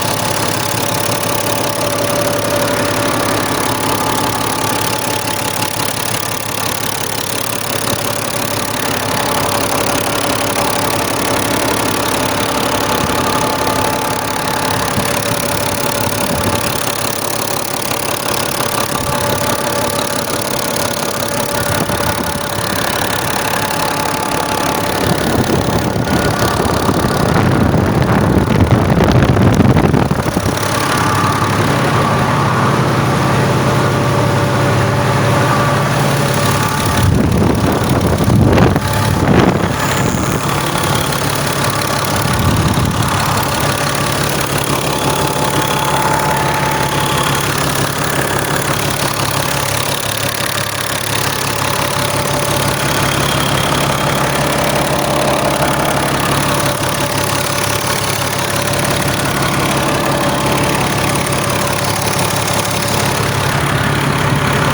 Наши сервисные специалисты провели анализ записи звука работающего трактора МТЗ-2022, через систему Endisound.
• Звук: "проскальзывание" на нагрузке >1600 об/мин